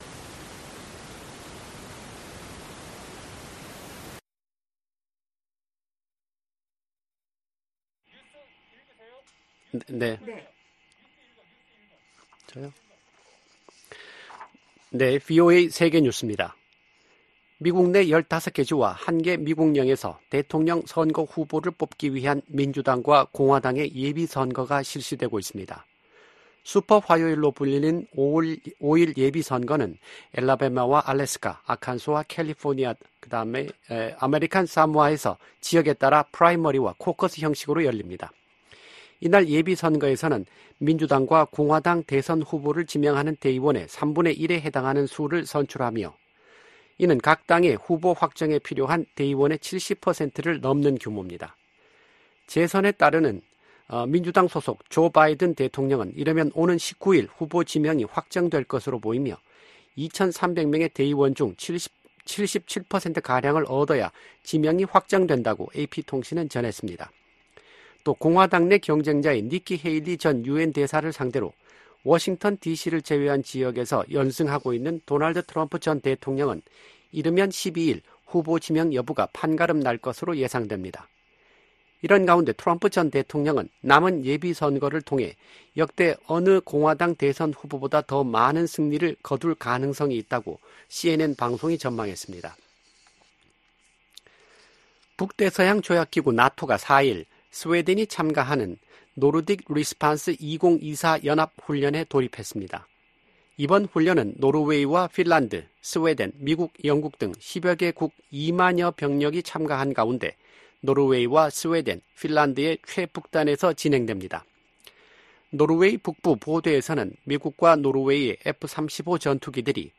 VOA 한국어 '출발 뉴스 쇼', 2024년 3월 6일 방송입니다. 북한의 영변 경수로 가동 움직임이 계속 포착되고 있다고 국제원자력기구(IAEA)가 밝혔습니다. 북한에서 철수했던 유럽 국가들의 평양 공관 재가동 움직임에 미국 정부가 환영의 뜻을 밝혔습니다. 북한은 4일 시작된 미한 연합훈련 '프리덤실드(FS)'가 전쟁연습이라고 주장하며 응분의 대가를 치를 것이라고 위협했습니다.